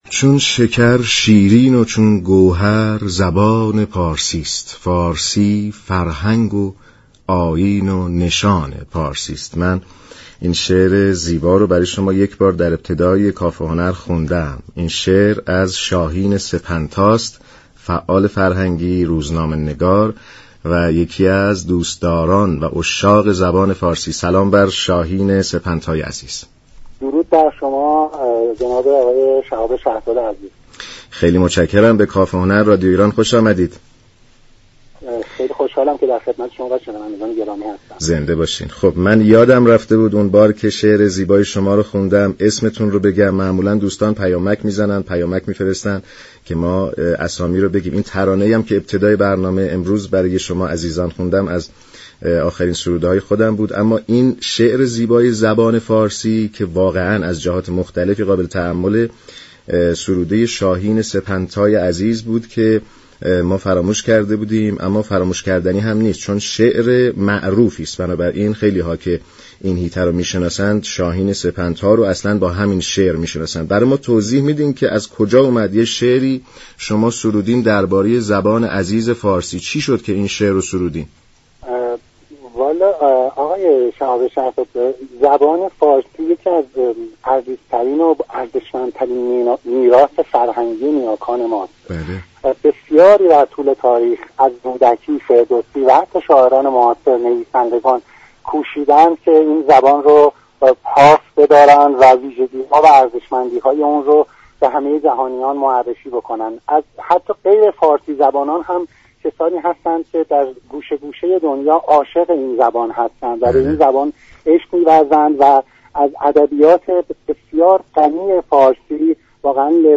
شاعر و ترانه سرای زبان پارسی در گفت و گو با رادیو ایران گفت: با افتخار فراوان علاقمند شركت در مسابقه رادیو ایران هستم. پشتیبانی از این گونه فعالیت های فرهنگی وظیفه همه ما شاعران است.